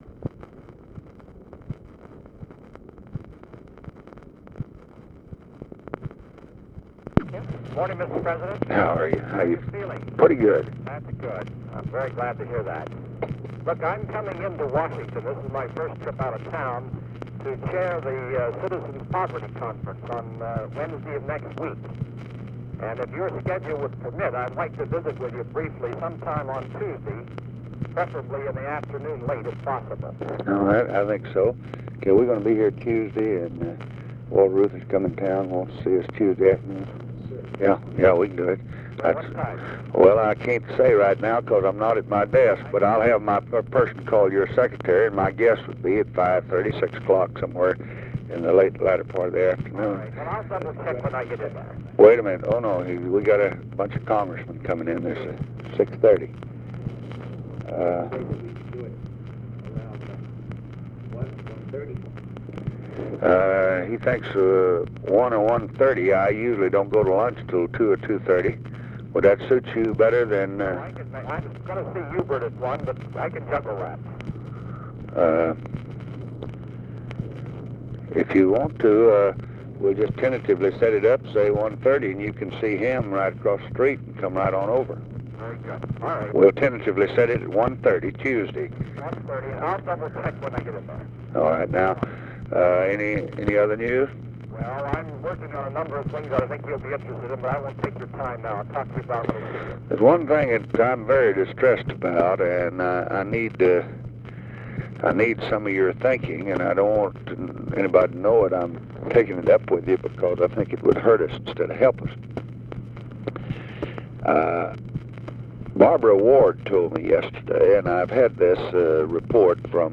Conversation with WALTER REUTHER and OFFICE CONVERSATION, February 5, 1965
Secret White House Tapes